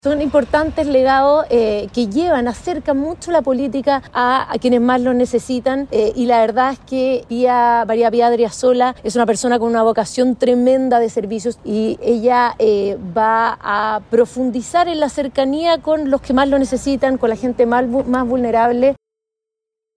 Frente a ello, la vocera de la Oficina del Presidente Electo, Mara Sedini, defendió la reinstalación del cargo, señalando que se trata de una tradición cercana a la ciudadanía y que será asumido por la esposa de Kast, María Paz Adriazola.